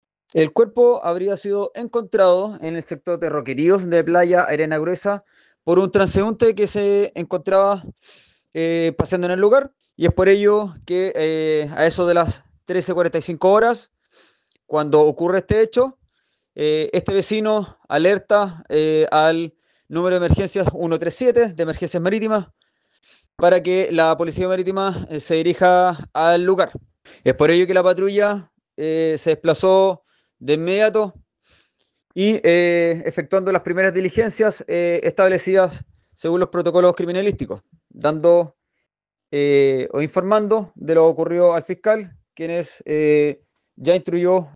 Insistió el oficial de la Armada que en la oportunidad una persona que paseaba por el lugar fue quien dio aviso oportuno a la institución naval.